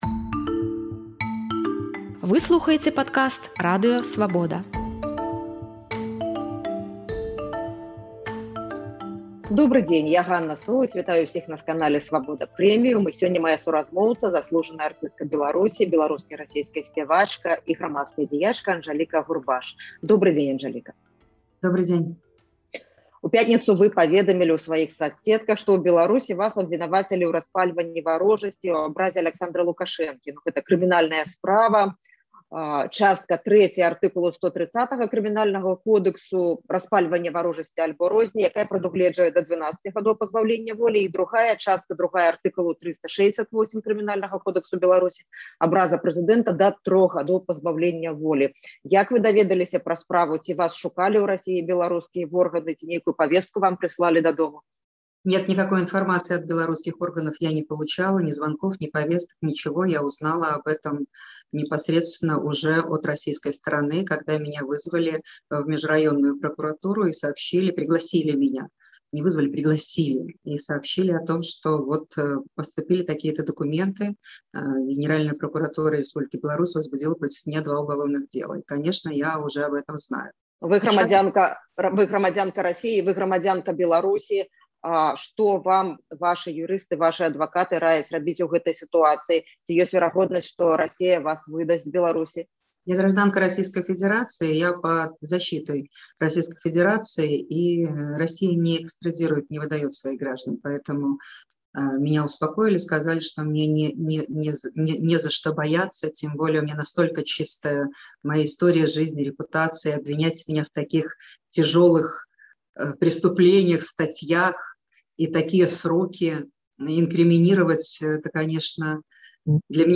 Заслужаная артыстка Беларусі, беларуская і расейская сьпявачка і грамадзкая дзяячка Анжаліка Агурбаш у эфіры Свабоды Premium пракамэнтавала выстаўленыя ёй у Беларусі абвінавачваньні ў распальваньні варожасьці і ў абразе Аляксандра Лукашэнкі.